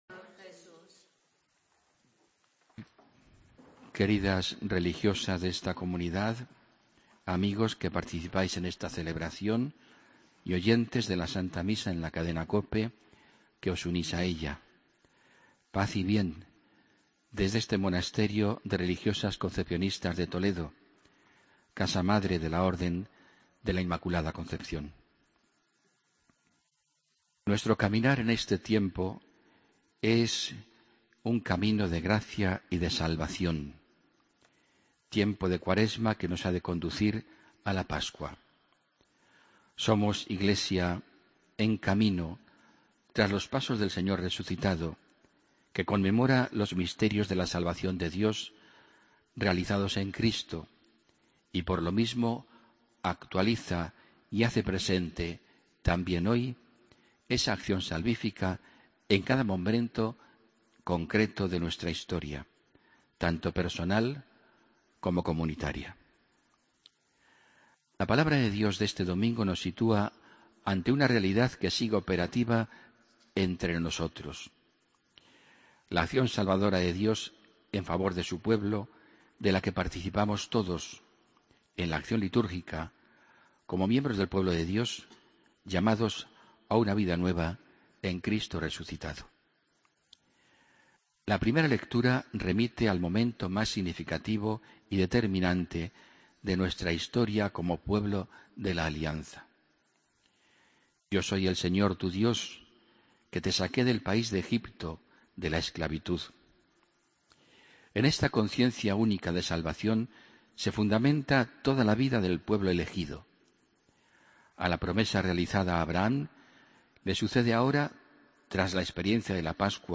Homilía del domingo 8 de marzo de 2015